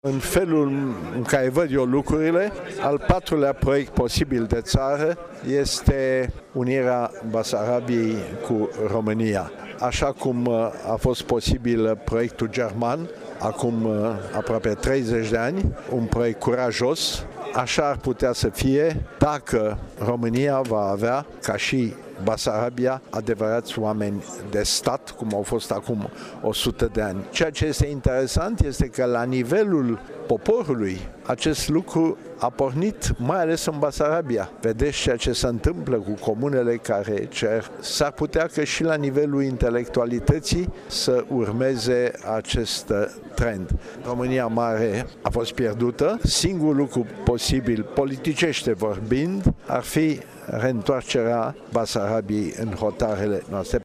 Afirmaţia a fost făcută, astăzi, de academicianul Răzvan Theodorescu în deschiderea lucrărilor Congresului Internaţional organizat de Universitatea Apollonia din Iaşi:
Zilelele Universităţii Apollonia din Iaşi sunt organizate sub egida Centenarului Întregirii Neamului şi reunesc personalităţi din România, Republica Moldova şi Regiunea Cernăuţi din Ucraina.